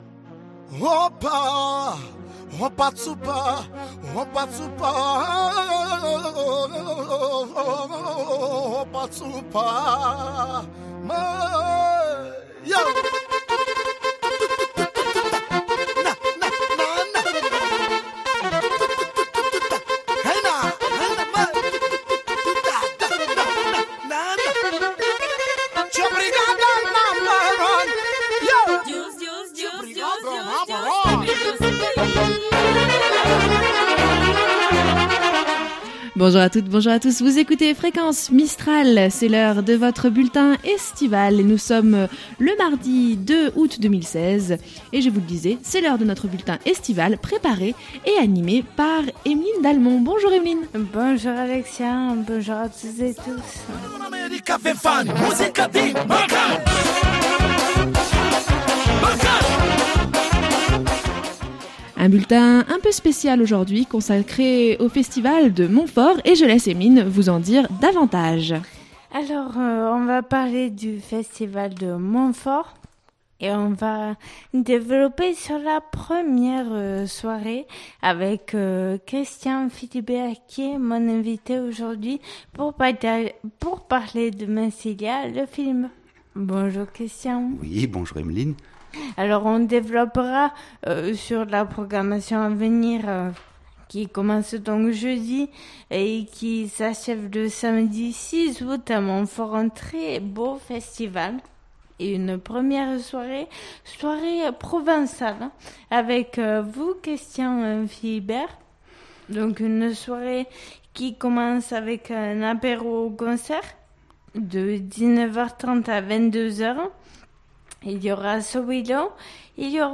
Un bulletin un peu spécial aujourd’hui, consacré au Festival de Montfort, et à la soirée d’ouverture avec l’avant première de la projection de « massilia le film »